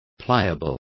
Complete with pronunciation of the translation of pliable.